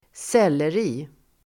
selleri substantiv, celery Uttal: [(²)s'el:eri] Böjningar: sellerin Definition: rotfrukten Apium graveolens Sammansättningar: sellerirot (celeriac) celery substantiv, selleri Förklaring: rotfrukten Apium graveolens